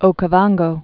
(ōkə-vänggō) also Cu·ban·go (k-bänggō)